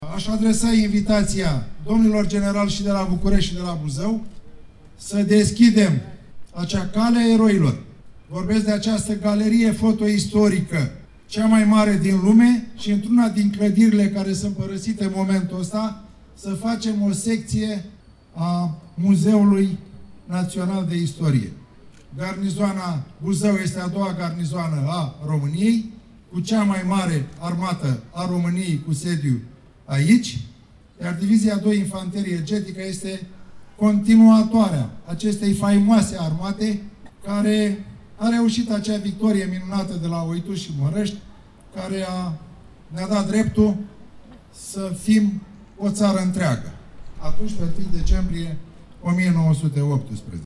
În cursul săptămânii trecute, la inaugurarea bustului mareșalului Alexandru Averescu, comandant al Armatei Române în timpul Primului Război Mondial şi una dintre cele mai cunoscute figuri româneşti de la începutul secolului al XX-lea, dar și cu ocazia aniversării a 103 ani de la înființarea Armatei a 2-a, primarul municipiului Buzău, Constantin Toma și-a exprimat dorința de a fi amenajat în viitor, pe Calea Eroilor din oraș, în clădirile unităților militare, un muzeu dedicat Armatei Române.